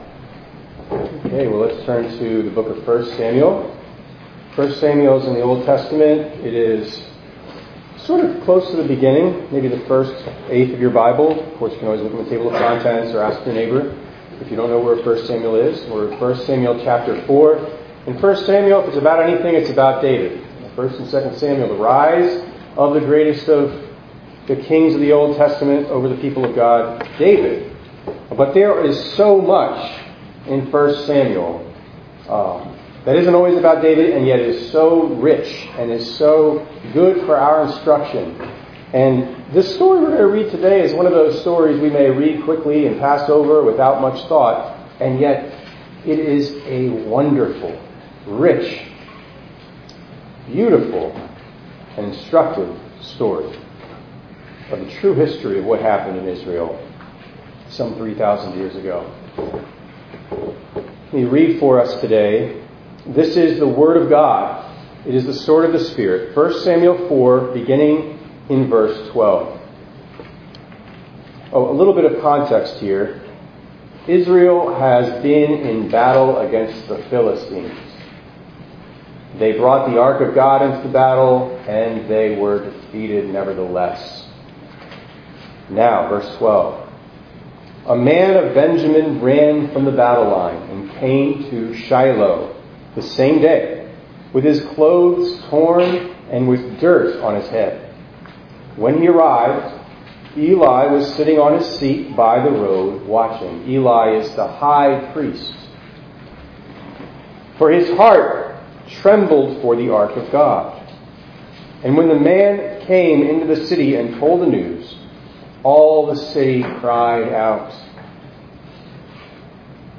9_8_24_ENG_Sermon.mp3